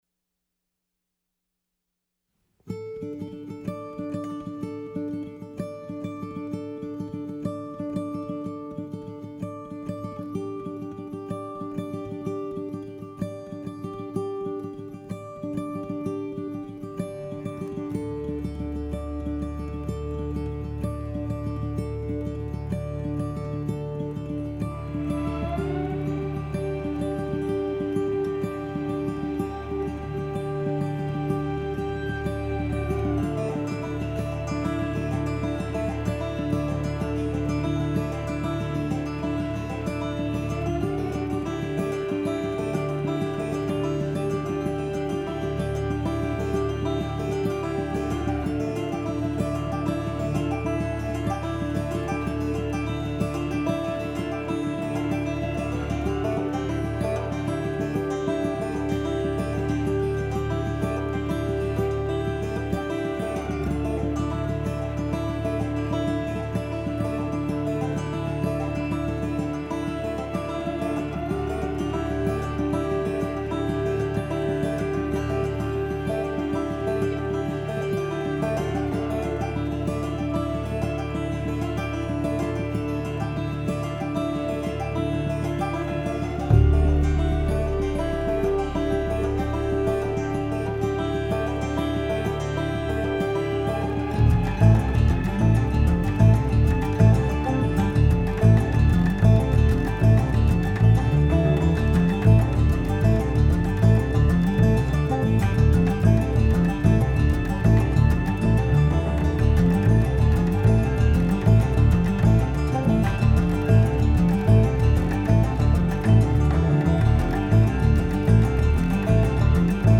guitar, cello, banjo, vocals
drums
Pittsfield, NH